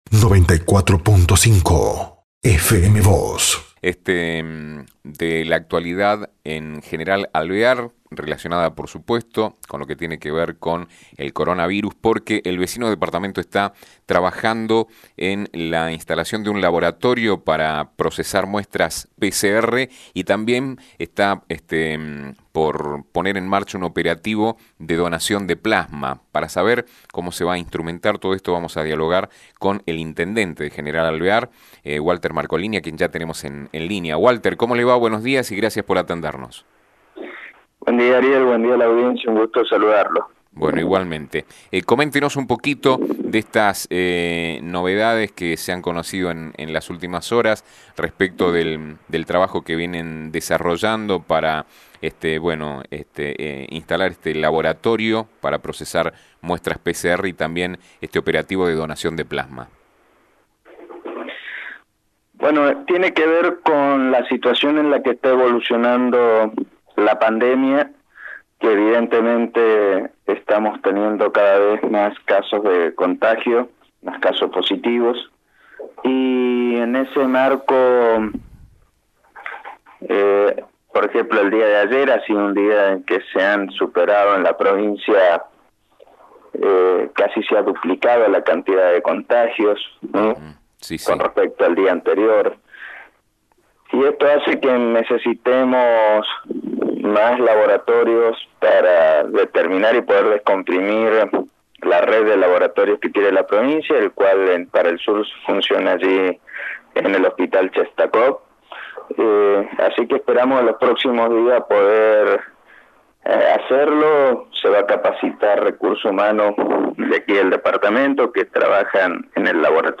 General Alvear está trabajando en un laboratorio destinado a procesar muestras PCR (sigla en inglés de “Reacción en Cadena de la Polimerasa”), además de poner en marcha un operativo de donación de plasma a fin de intensificar sus trabajos contra el coronavirus. Habló con FM Vos (94.5) y Diario San Rafael eI Intendente de ese departamento, Walther Marcolini, quien espera que todo eso se pueda concretar en los próximos días para descomprimir otros laboratorios, mientras se realizan las capacitaciones pertinentes.